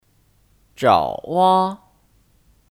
爪哇 Zhǎowā (Kata benda): Pulau Jawa